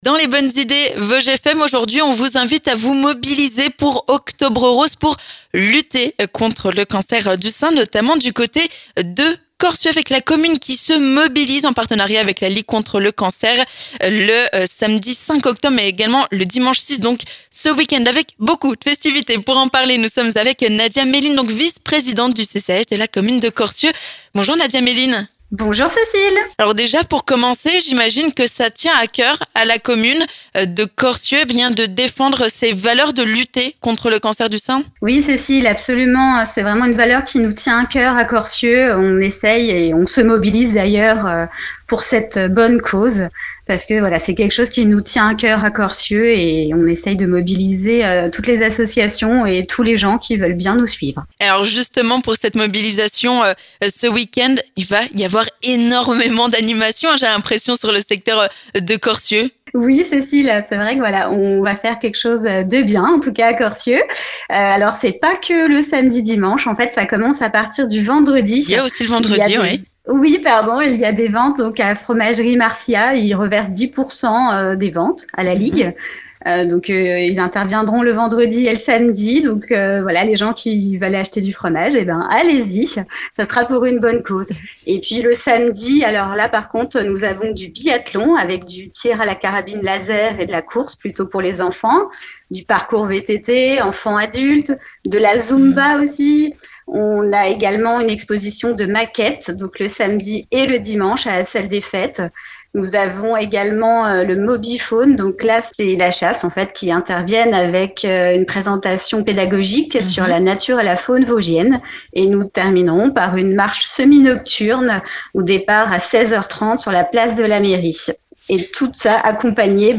%%La rédaction de Vosges FM vous propose l'ensemble de ces reportages dans les Vosges%%